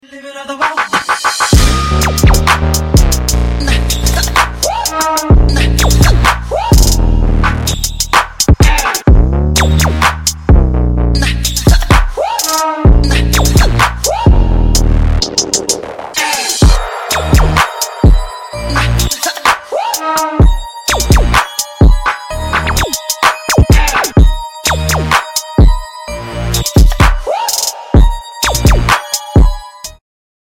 • Качество: 256, Stereo
Electronic
без слов
Trap
Bass
Электронный бит и бас для танца